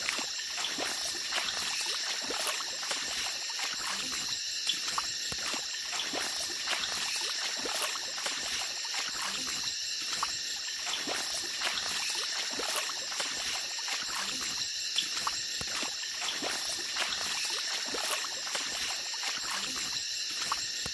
Tiếng Bước Chân đi trong Nước vào ban Đêm
Thể loại: Tiếng động
tieng-buoc-chan-di-trong-nuoc-vao-ban-dem-www_tiengdong_com.mp3